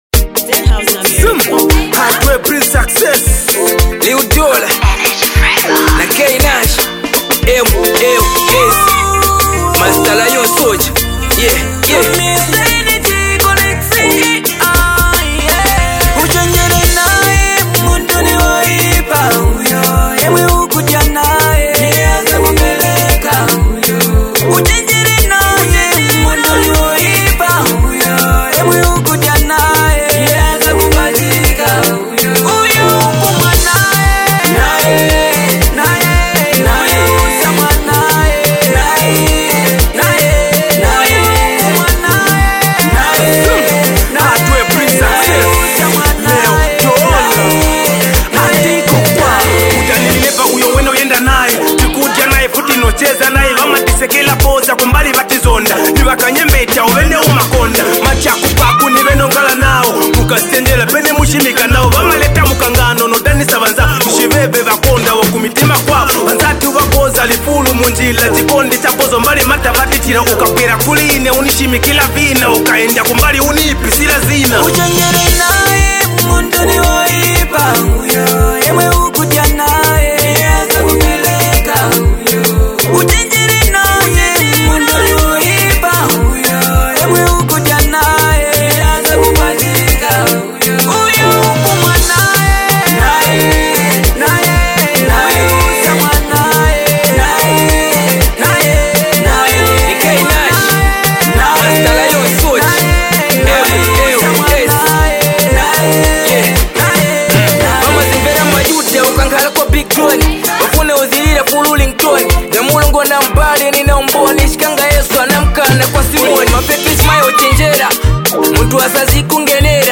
a powerful banger filled with energy, emotion and pure fire.